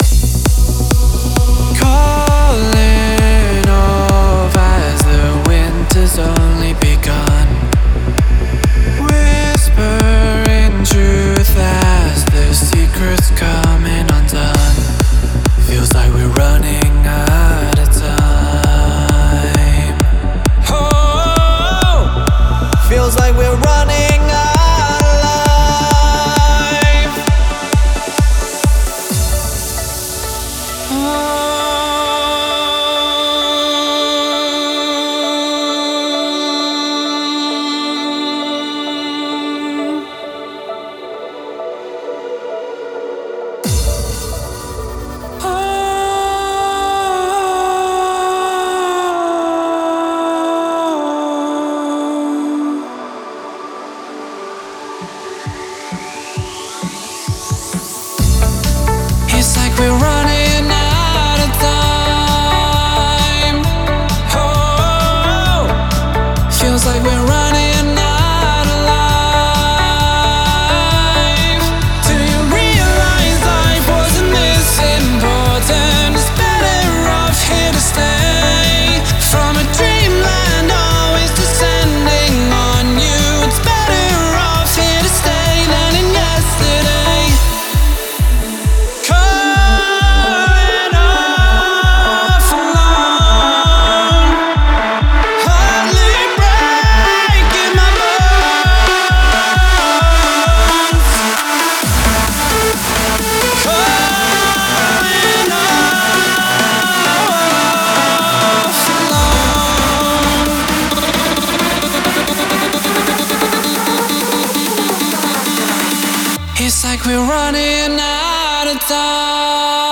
это трек в жанре электронной музыки